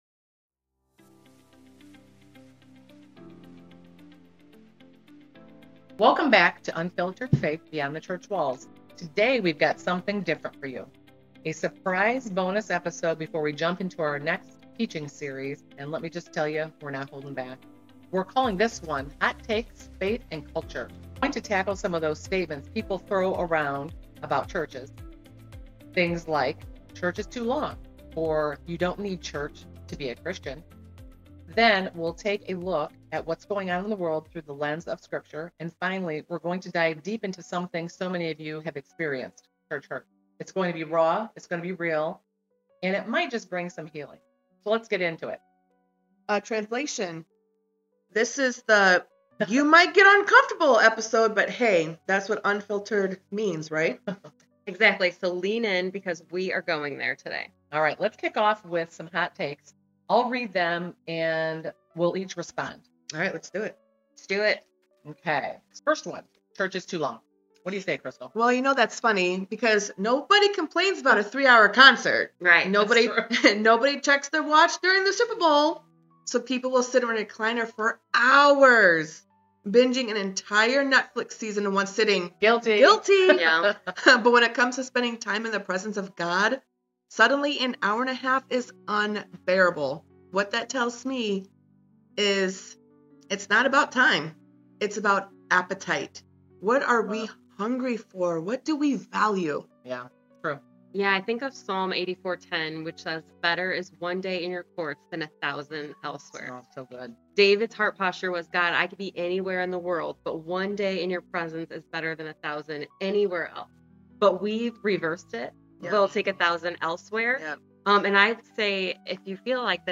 A raw, unfiltered Christian podcast tackling hot takes, culture clashes, and church hurt—sharing truth, grace, and hope in Jesus Christ.